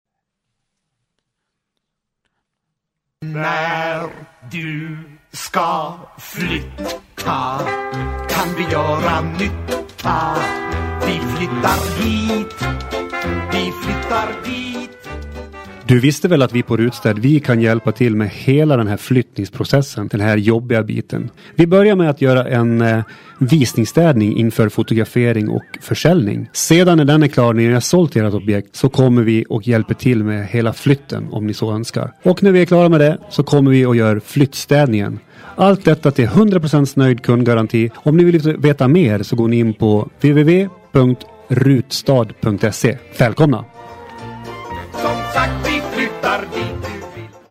Välkomna att lyssna på vår senaste radioreklam...
rutstad-radiodjingel.mp3